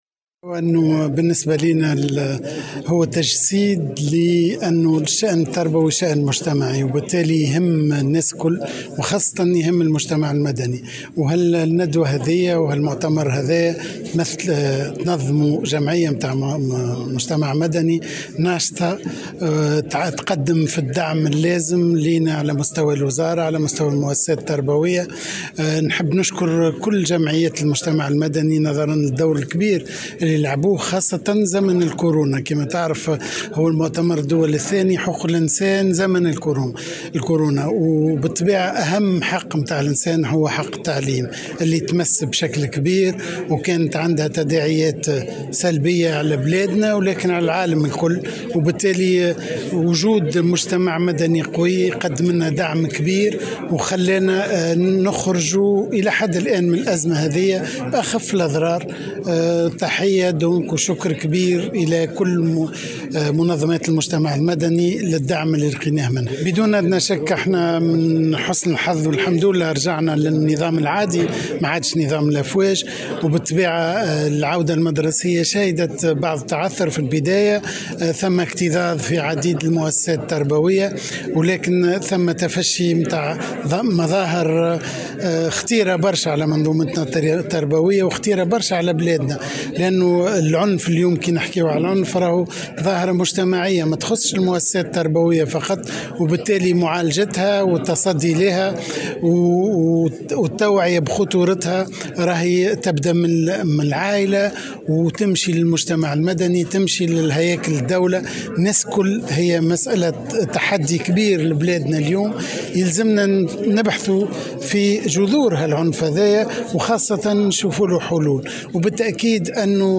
شارك وزير التربية فتحي السلاوتي اليوم الأحد في أشغال المؤتمر الدولي الثاني للجمعية الدولية للدفاع عن حقوق الانسان والاعلام، وبيّن في تصريح للجوهرة أف أم أن المجتمع المدني كان له دور كبير في مساعدة الدولة على مجابهة جائحة كورونا.